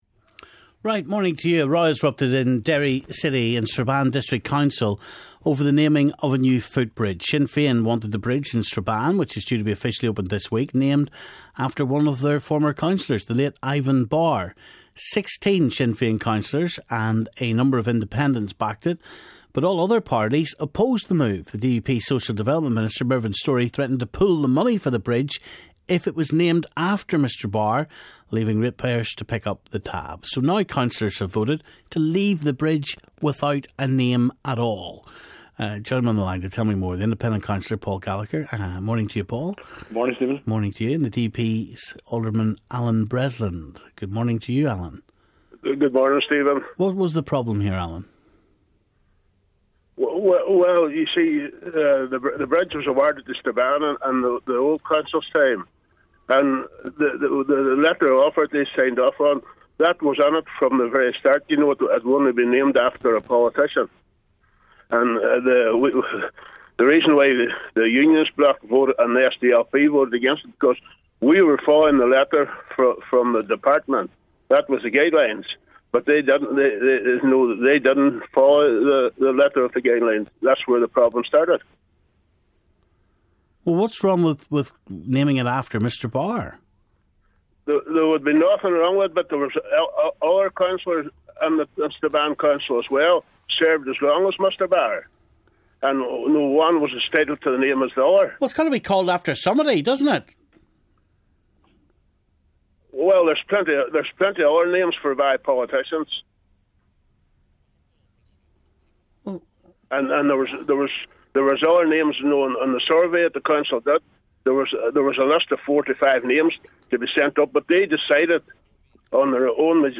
So now councillors have voted to leave the bridge without a name. Joining me on the line to tell me more is independent councillor, paul Gallagher and the DUP's Alderman Allan Bresland.